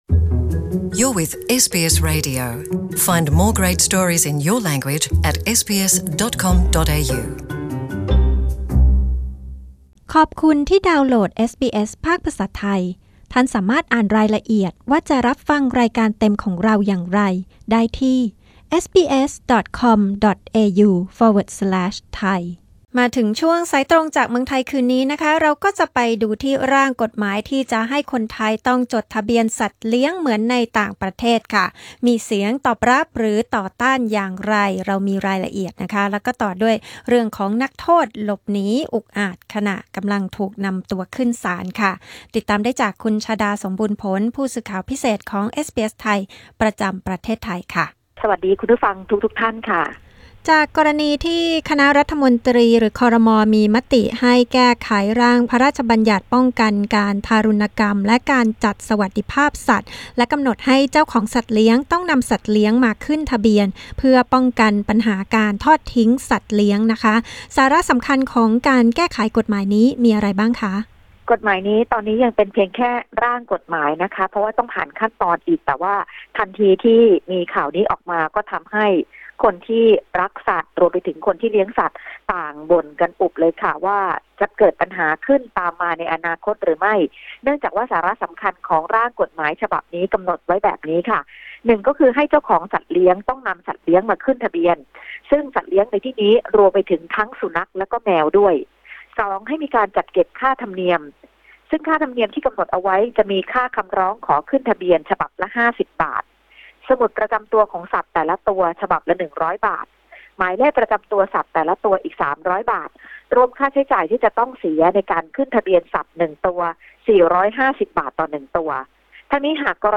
ข่าวสายตรงจากเมืองไทย 11 ต.ค.